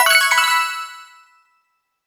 Super Mario Maker 2 - SM3DW 30-Coin.
}} Category:Super Mario Maker 2 sound effect media files You cannot overwrite this file.